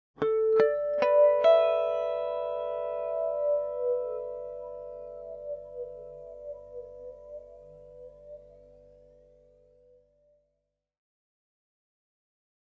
Electric Guitar Harmonic Arpeggio With Flange 2